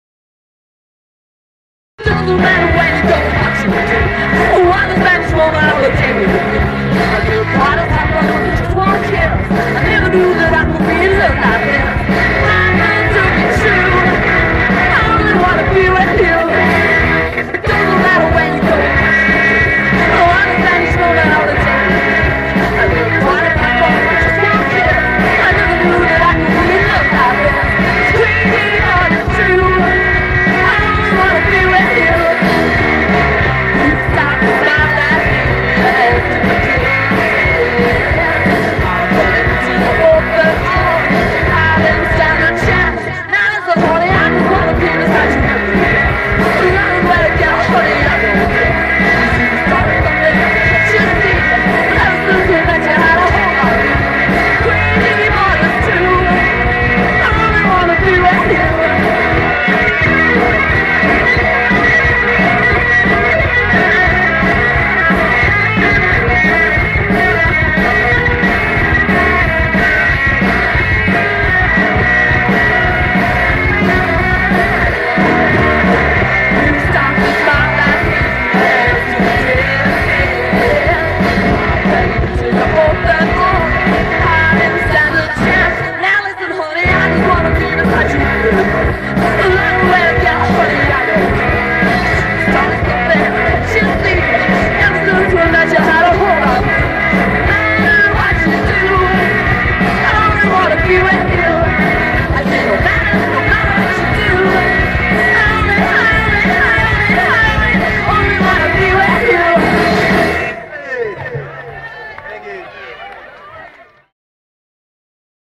rock
(1987) Recorded live.